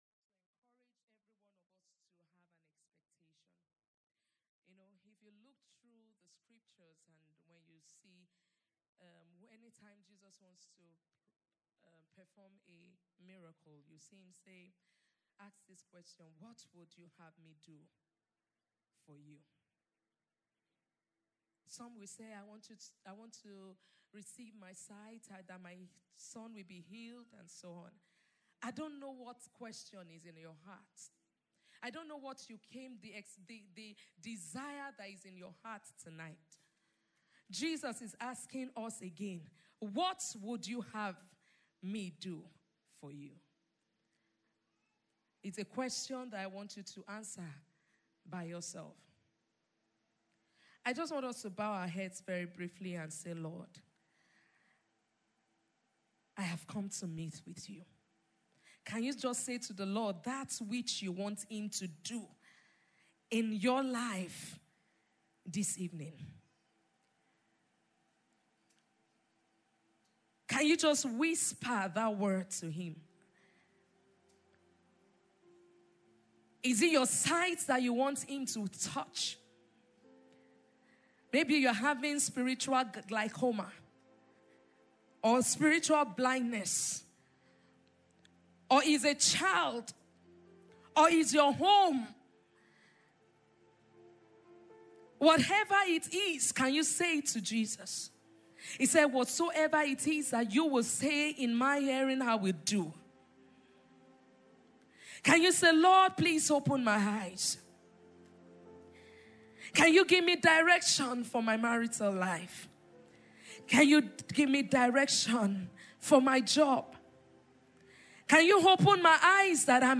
Sermons | RCN Lagos